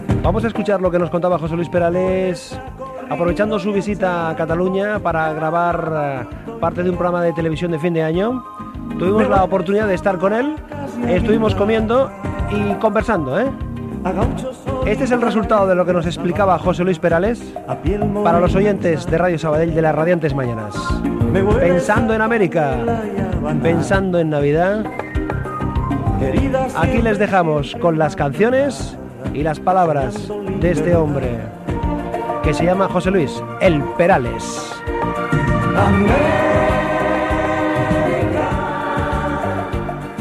Introducció a una entrevista al cantant José Luis Perales
Entreteniment